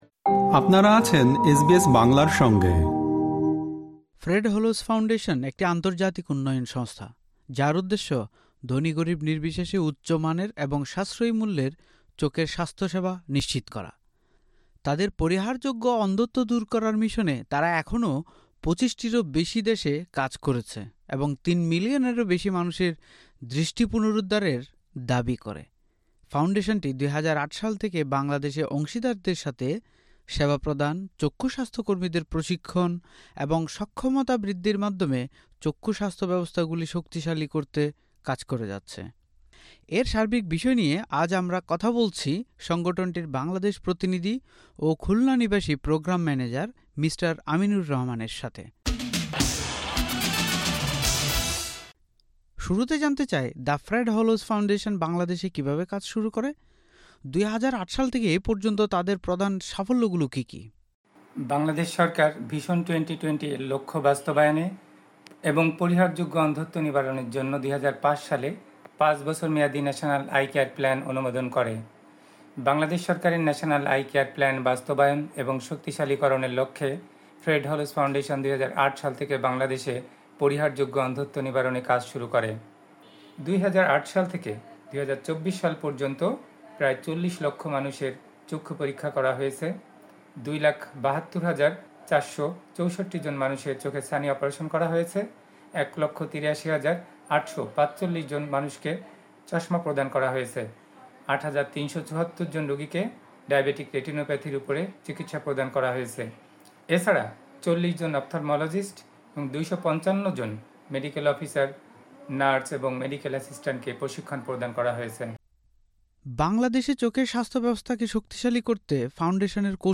এর সার্বিক বিষয় নিয়ে এসবিএস বাংলার সাথে কথা বলেছেন